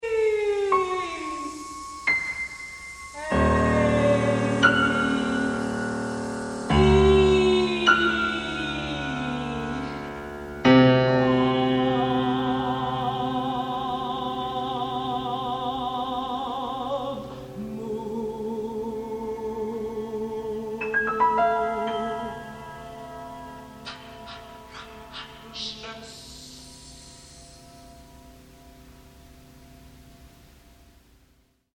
THREE PIECES FOR VOICE, CLARINET AND